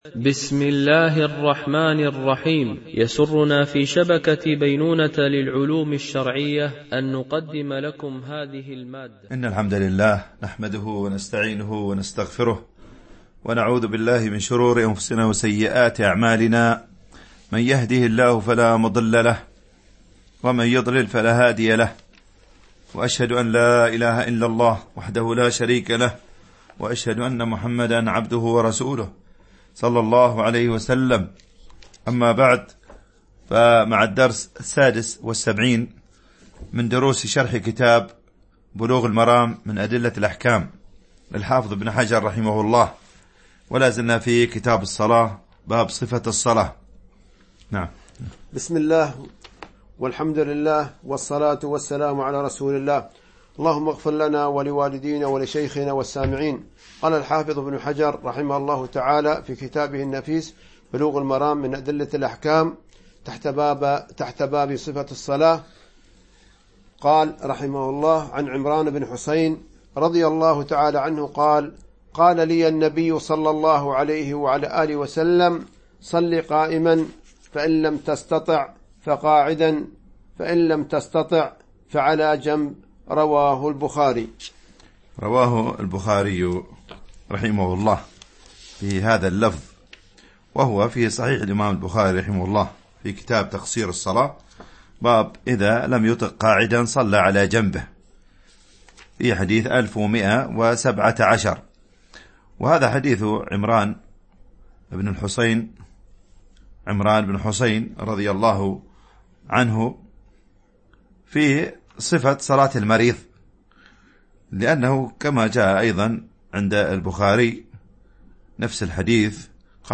شرح بلوغ المرام من أدلة الأحكام - الدرس 76 ( كتاب الصلاة - باب صفة الصلاة , الحديث 328 - 329 )